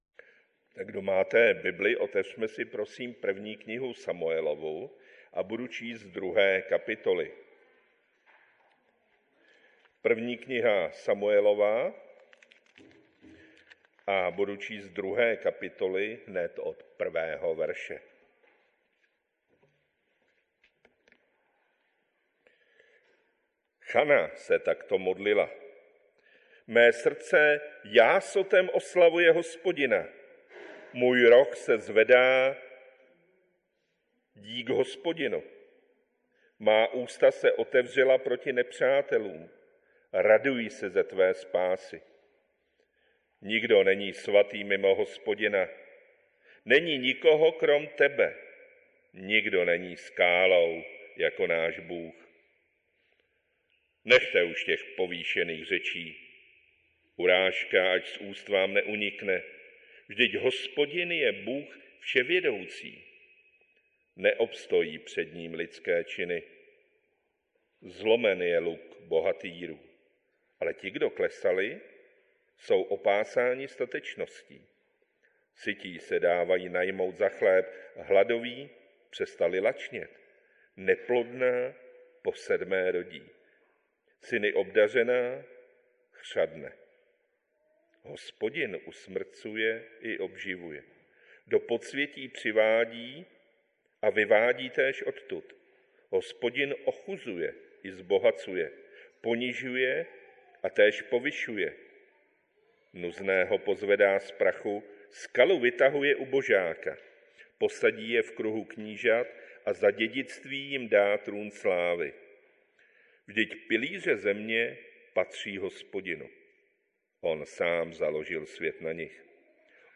Kategorie: Nedělní bohoslužby Husinec